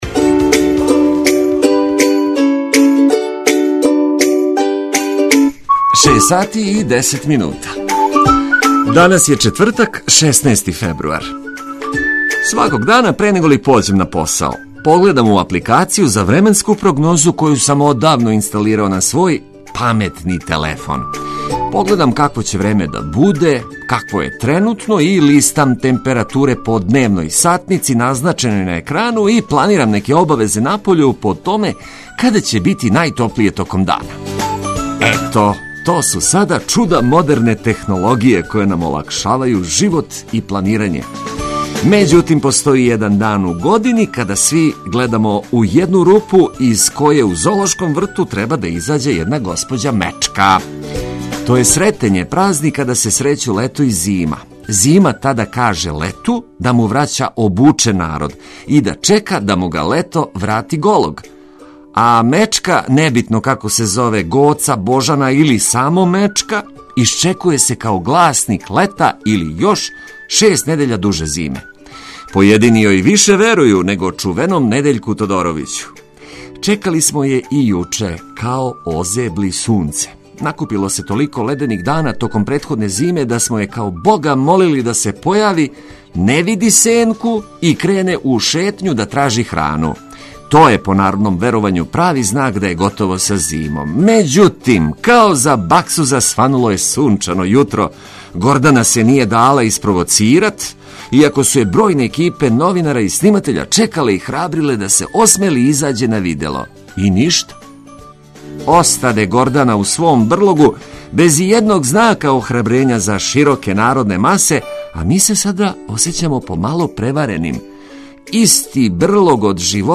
Током још једног "нерадног" јутра слушаћете сјајну музику за буђење прошарану корисним информацијама.